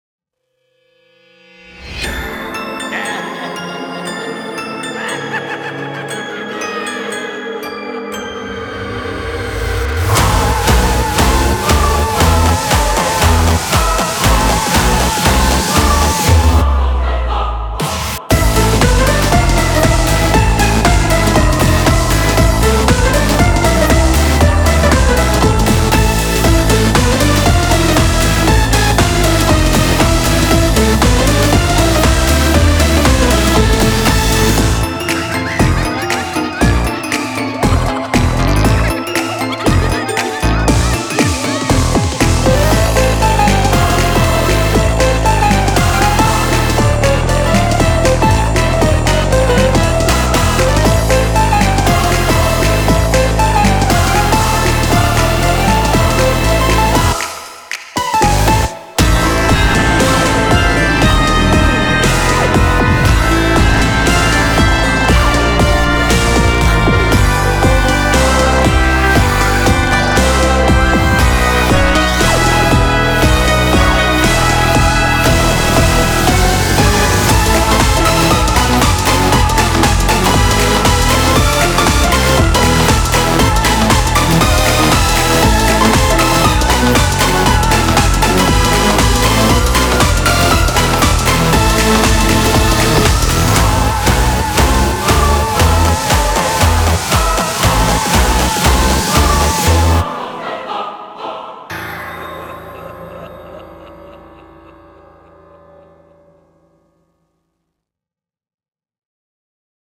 BPM118
Audio QualityLine Out